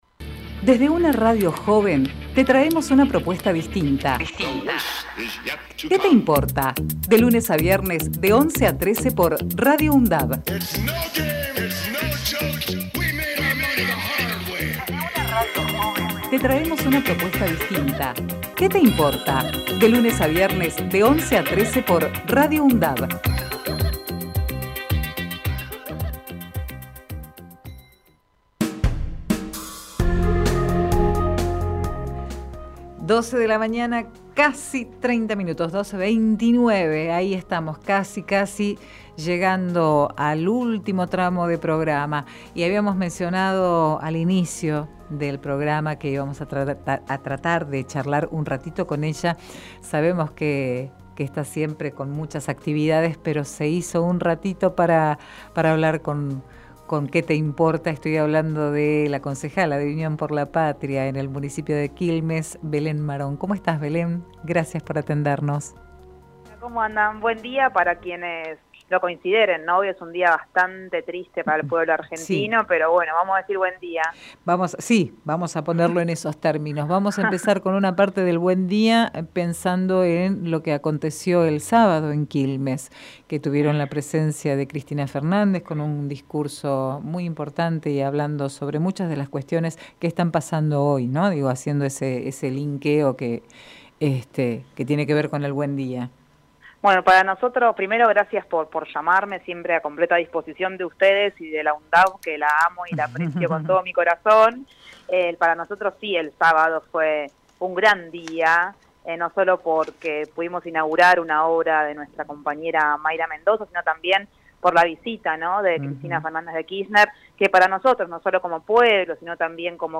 QUÉ TE IMPORTA - BELÉN MARÓN Texto de la nota: Entrevista realizada en "Qué te Importa" a la Lic. Belén Marón, Concejala de Unión por La Patria por el Municipio de Quilmes Archivo de audio: QUÉ TE IMPORTA - BELÉN MARÓN Programa: Qué te importa?!